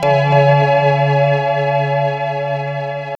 Synth Chord 02.wav